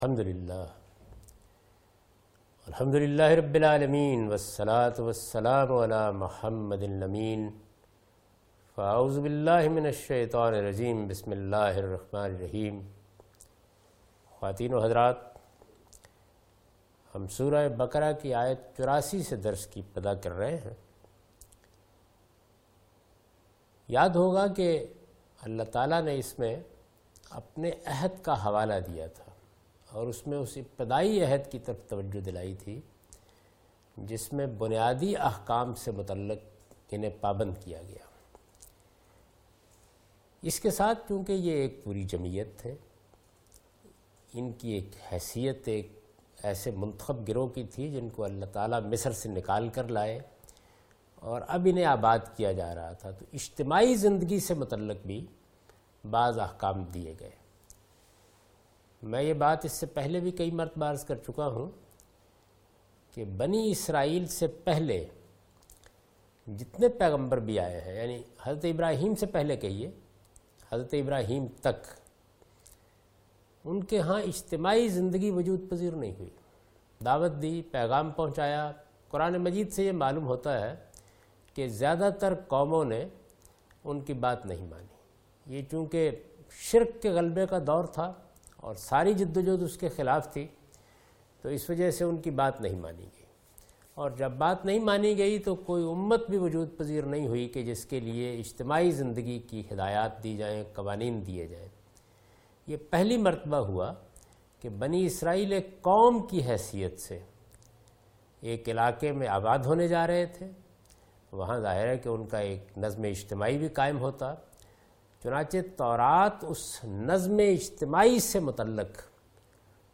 Surah Al-Baqarah - A lecture of Tafseer-ul-Quran – Al-Bayan by Javed Ahmad Ghamidi. Commentary and explanation of verse 84,85,86,87,88 and 89 (Lecture recorded on 17th Oct 2013).